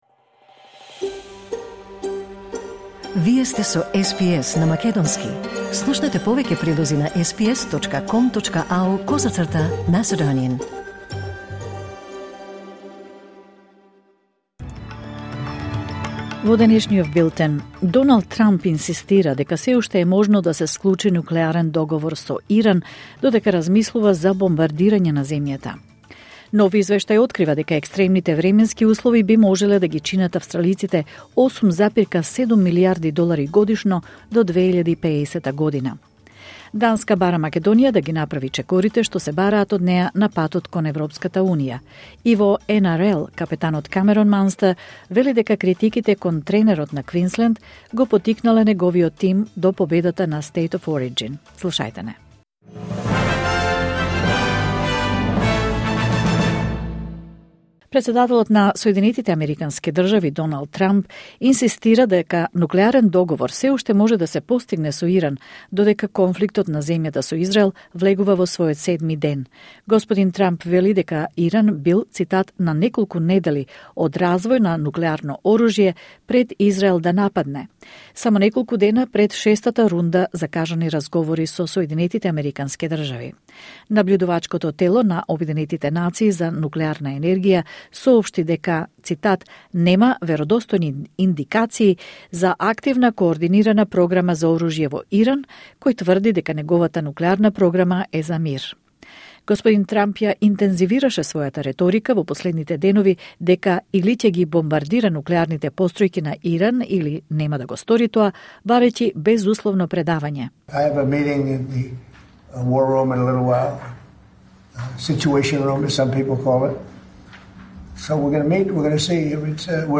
Вести на СБС на македонски 19 јуни 2025